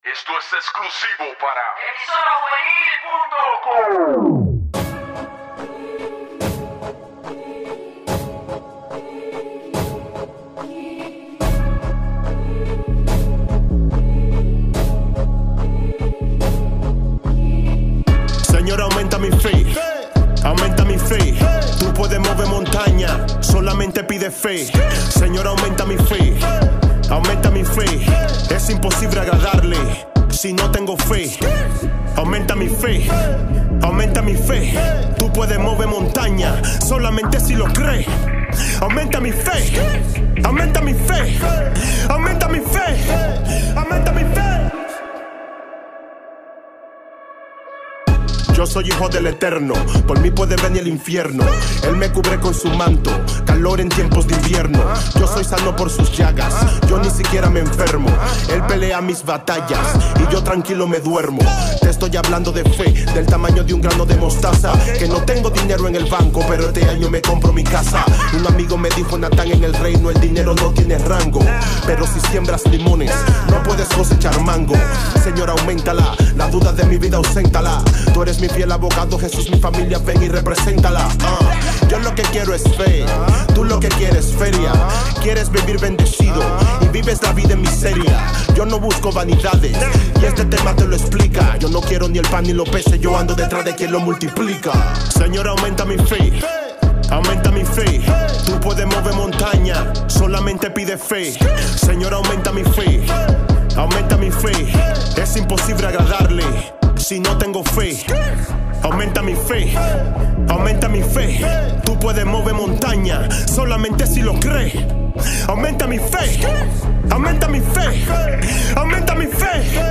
Musica Cristiana